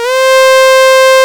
STRS C4 F.wav